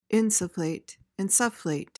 PRONUNCIATION:
(IN-suh-flayt, in-SUHF-layt)